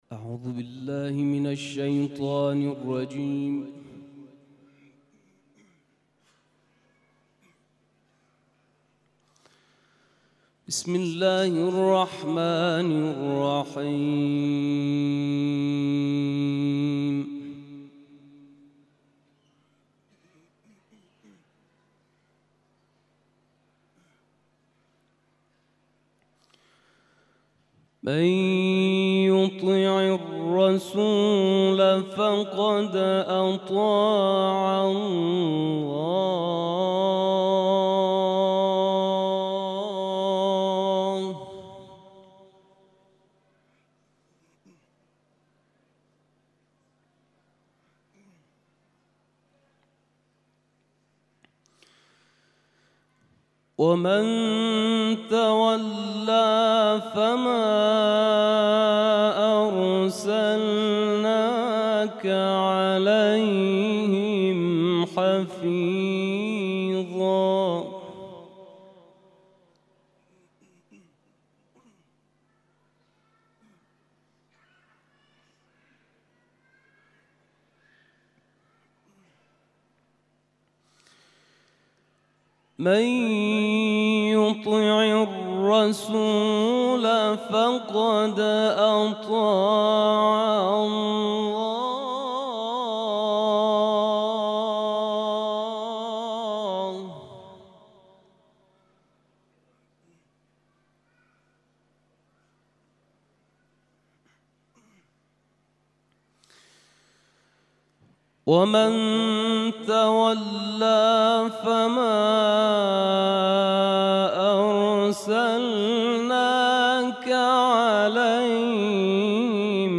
تلاوت قرآن کریم